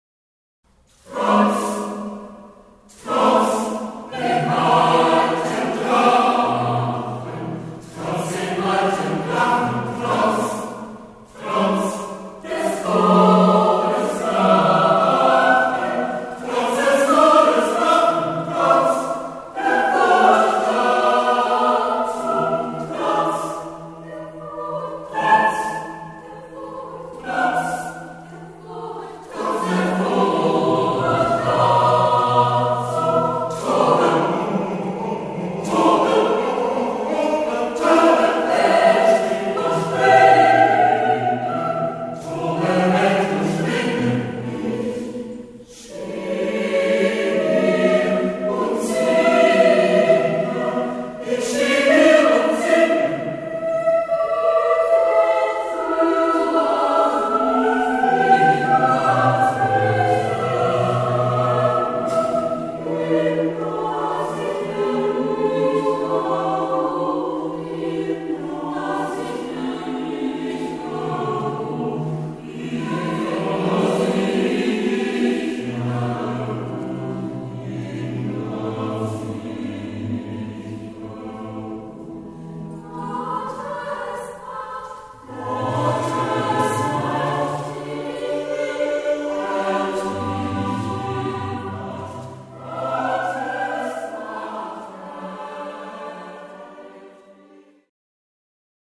Bach "Jesu, meine Freude", Konzertmitschnitt 2014